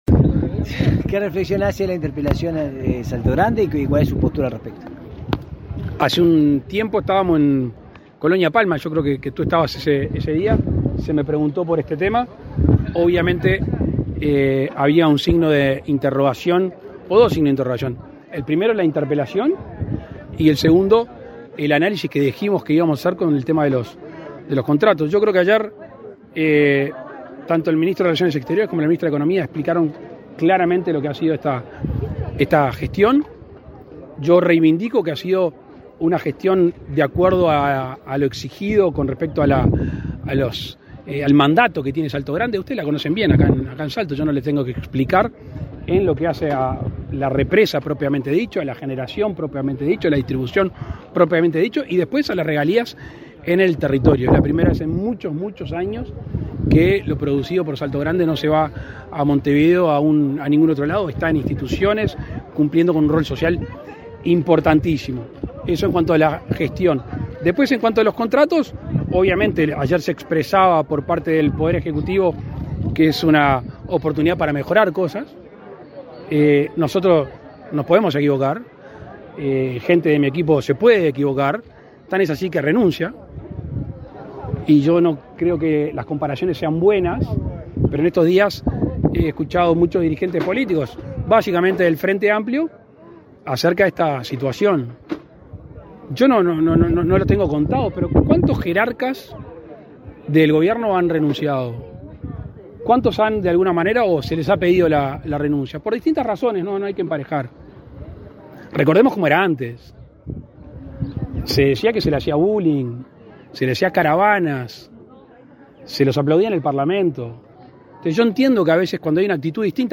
Declaraciones a la prensa del presidente de la República, Luis Lacalle Pou